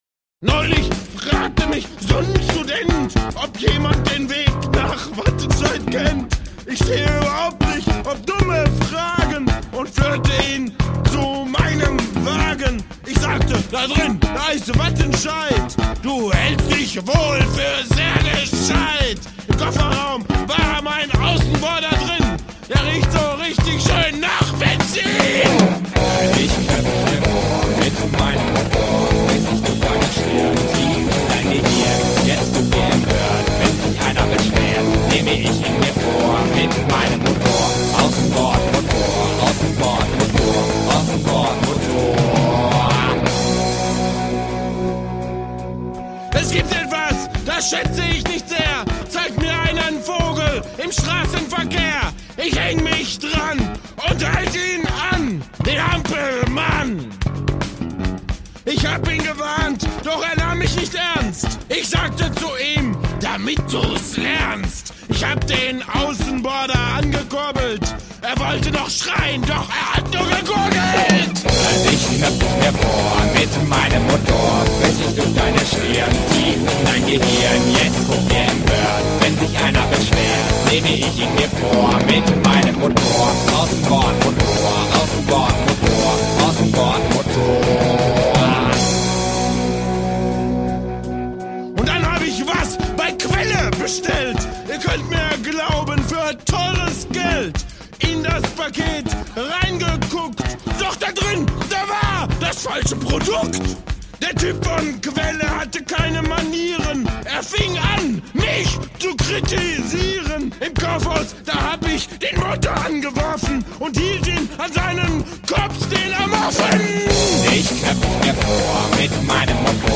Ich bitte außerdem zu entschuldigen, daß wir zum Ende hin bei zunehmendem Alkoholpegel scheinbar die Mikros gefressen haben und die Stimmen teilweise gut übersteuert sind….aber lustig war’s trotzdem!!